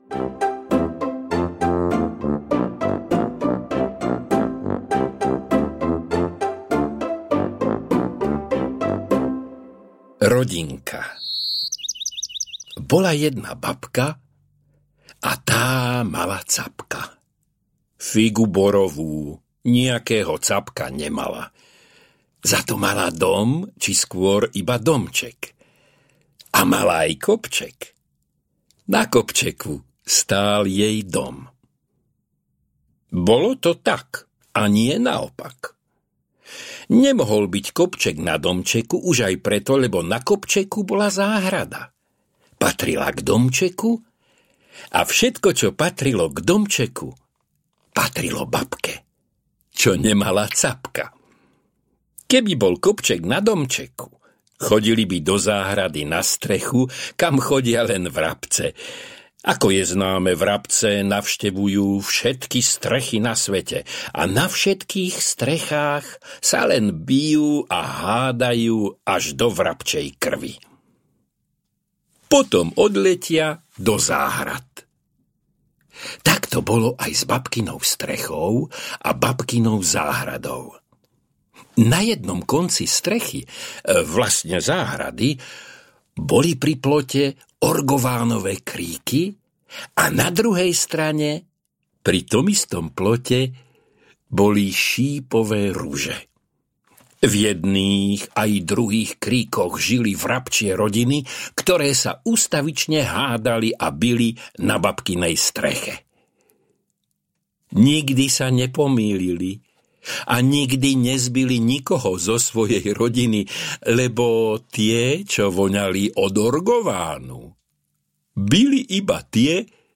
Babka na rebríku audiokniha
Ukázka z knihy
• InterpretFrantišek Kovár